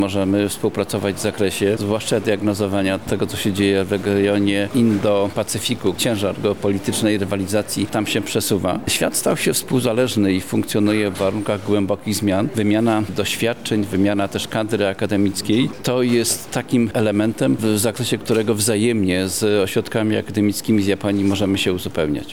Wydarzenie składało się z dwóch części: wystąpienia ambasadora na temat współpracy między Polską, a Japonią oraz sesji pytań od publiczności z auli.